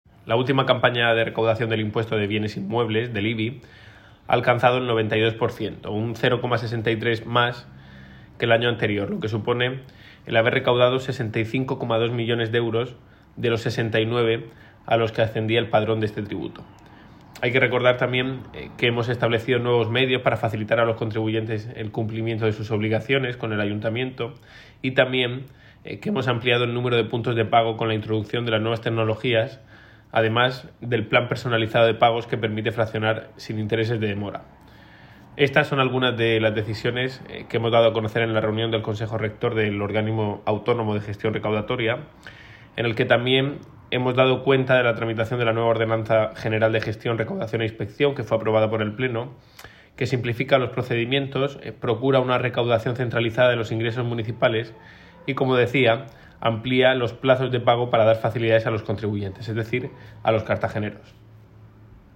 Enlace a Declaraciones del concejal de Hacienda, Nacho Jáudenes